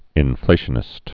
(ĭn-flāshə-nĭst)